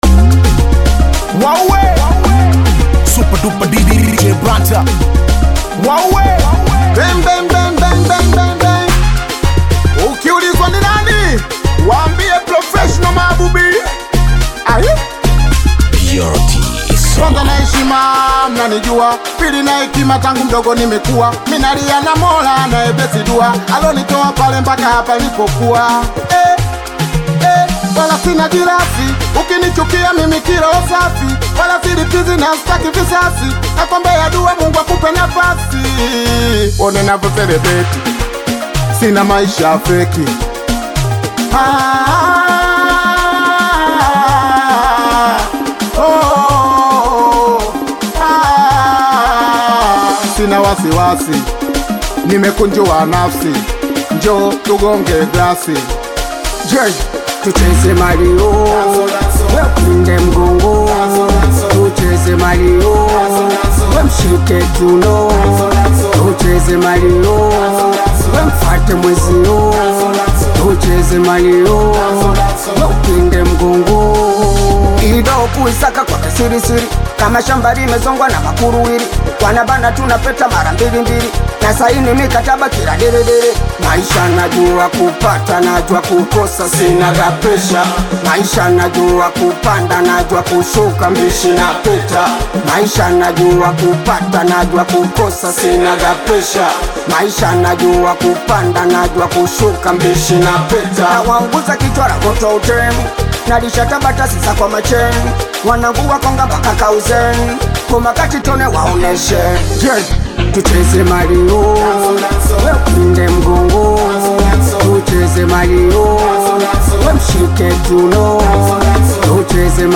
With its catchy hook and intricate instrumentation